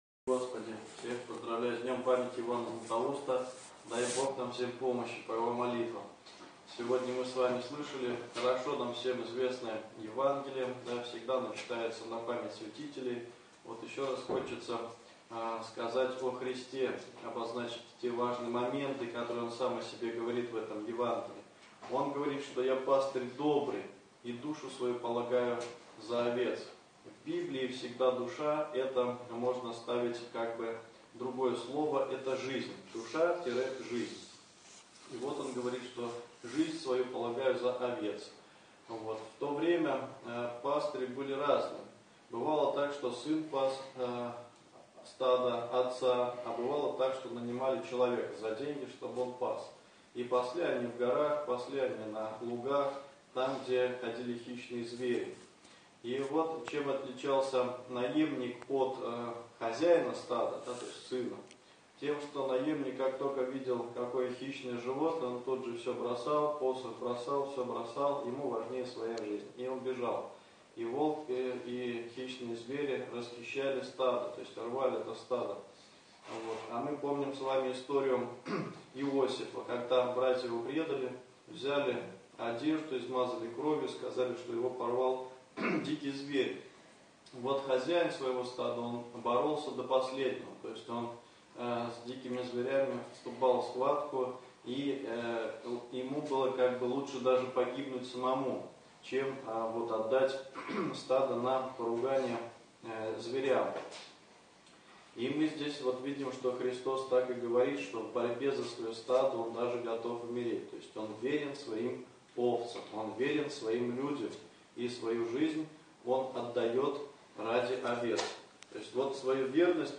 Аудио: проповедь